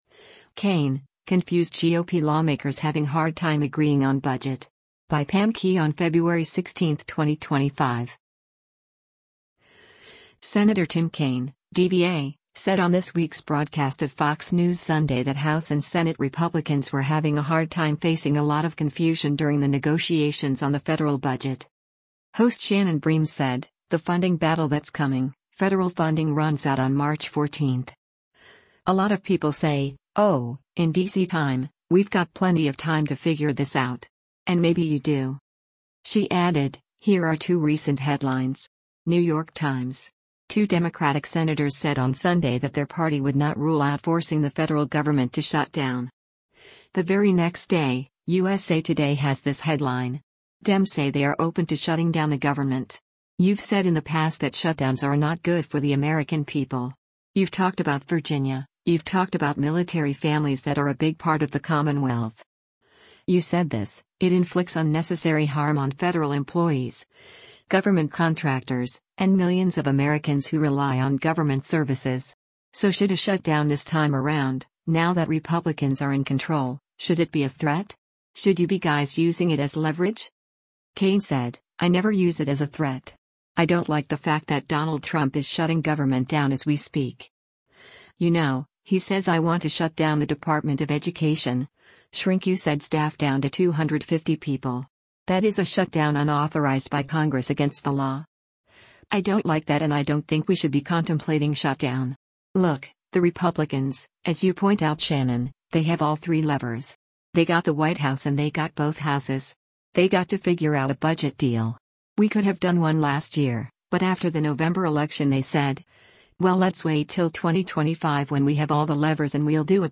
Senator Tim Kaine (D-VA) said on this week’s broadcast of “Fox News Sunday” that House and Senate Republicans were having a “hard time” facing “a lot of confusion” during the negotiations on the federal budget.